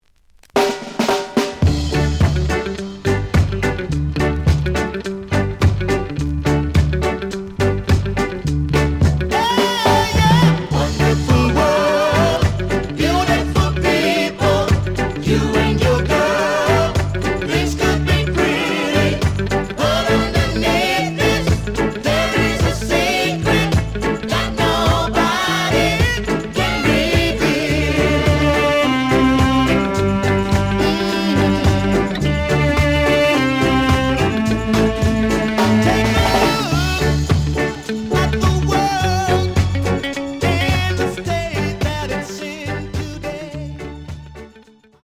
The audio sample is recorded from the actual item.
●Format: 7 inch
●Genre: Reggae